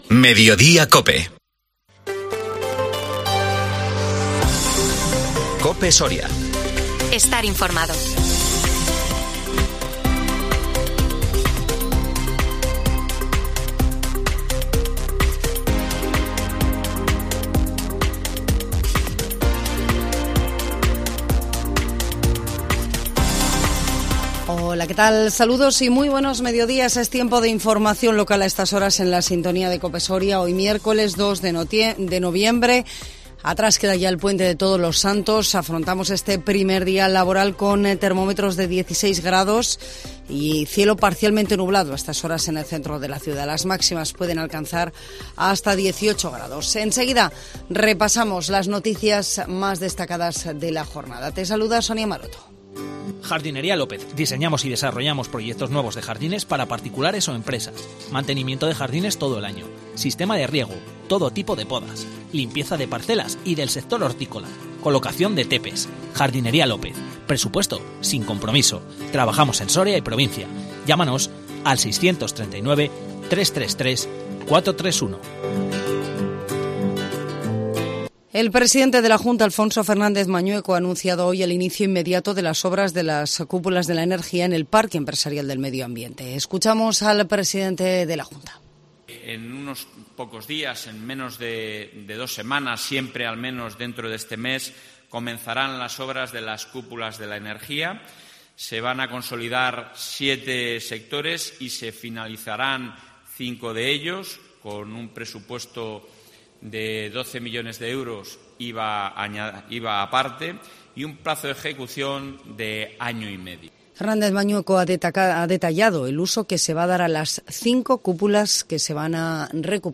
INFORMATIVO MEDIODÍA COPE SORIA 2 NOVIEMBRE 2022